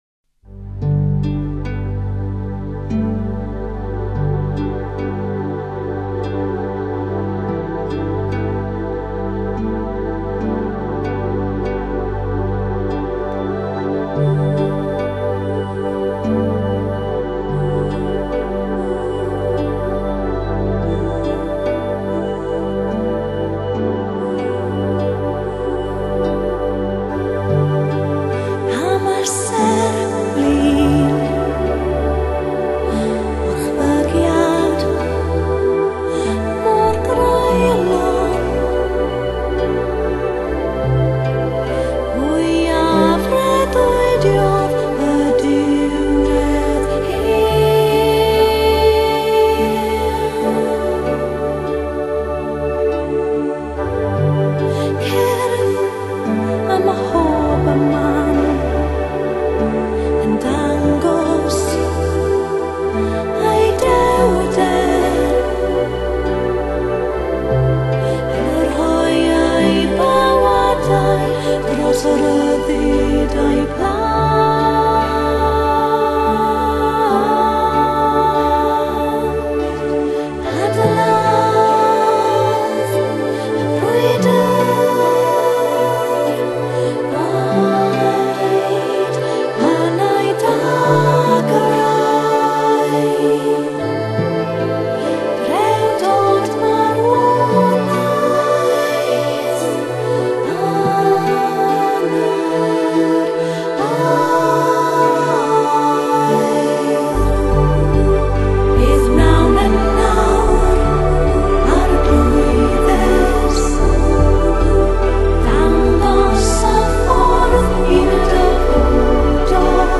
音乐类型：新世纪音乐
失传的神秘克尔特古语，华丽的多层次美声吟唱，融合古诗与现代重低音的历史音乐剧。